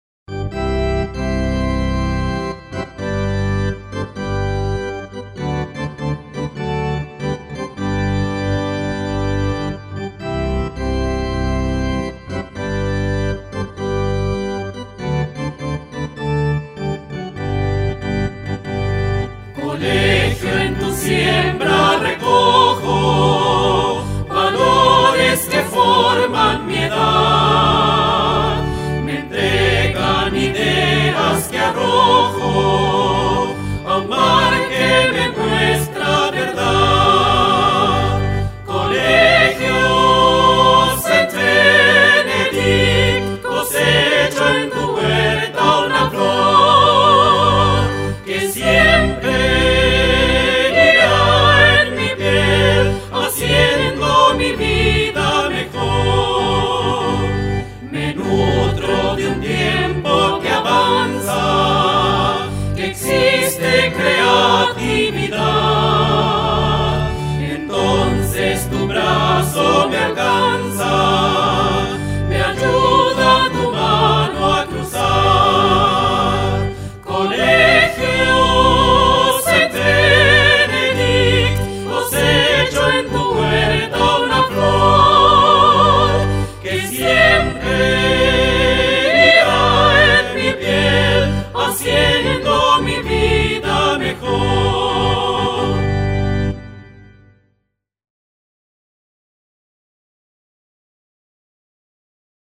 HIMNO DEL COLEGIO
himno.mp3